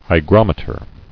[hy·grom·e·ter]